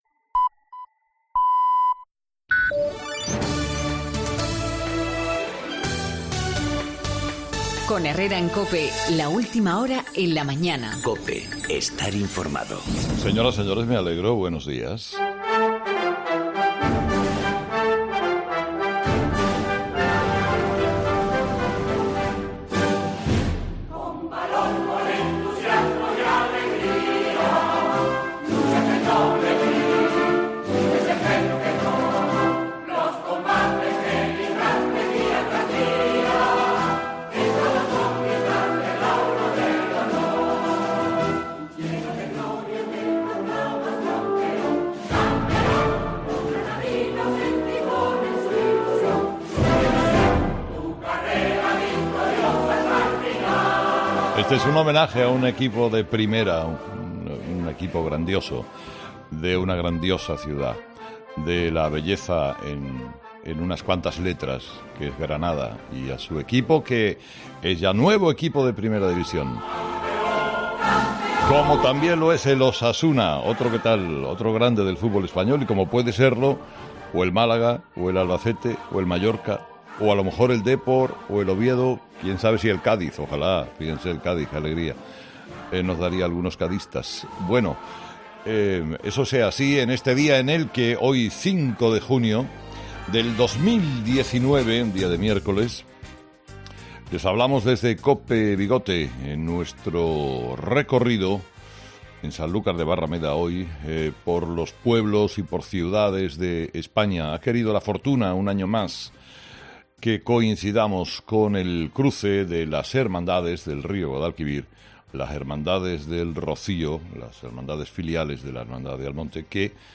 Desde COPE bigote - Sanlucar